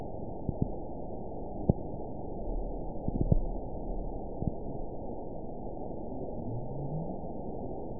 event 922375 date 12/30/24 time 05:16:54 GMT (11 months ago) score 9.41 location TSS-AB04 detected by nrw target species NRW annotations +NRW Spectrogram: Frequency (kHz) vs. Time (s) audio not available .wav